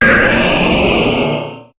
Cri de Méga-Laggron dans Pokémon HOME.